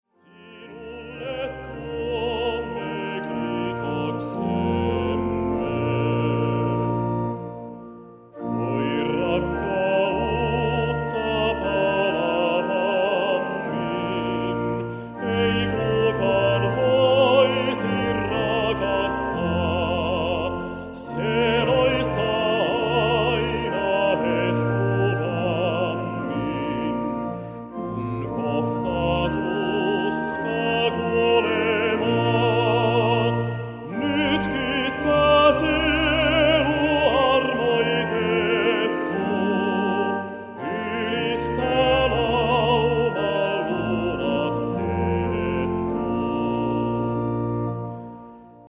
Siionin lauluja